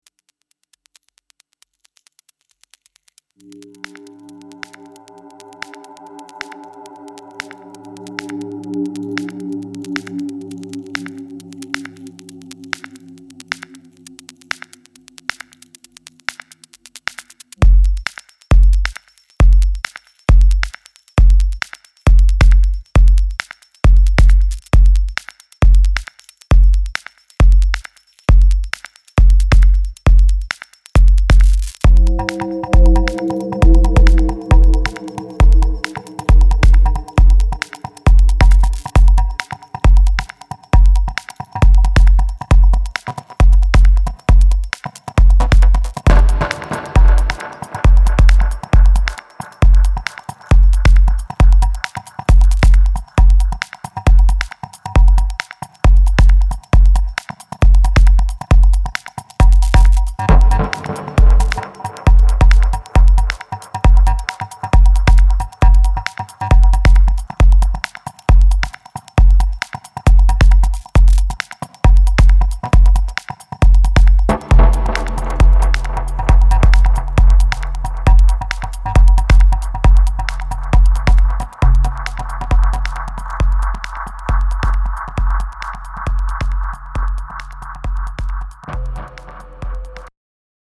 暗黒ミニマル・ダブ・ガラージ